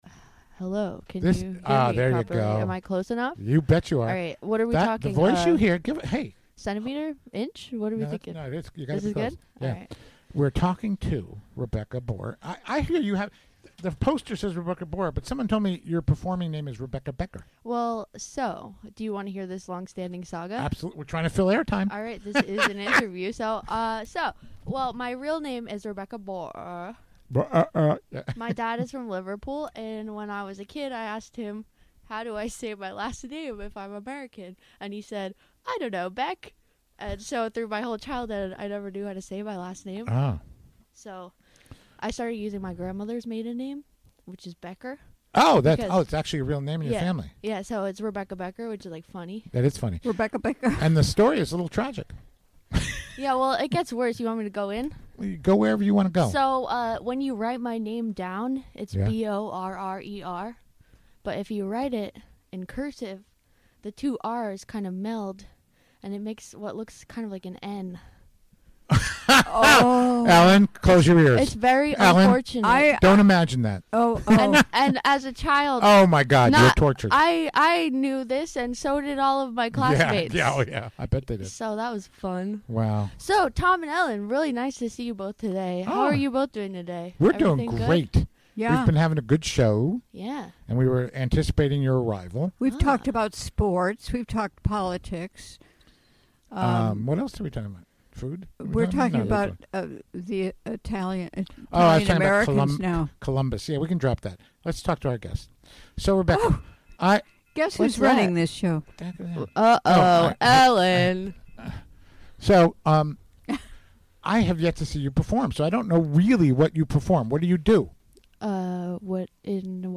Recorded live during the WGXC Afternoon Show Thursday, April 19, 2018.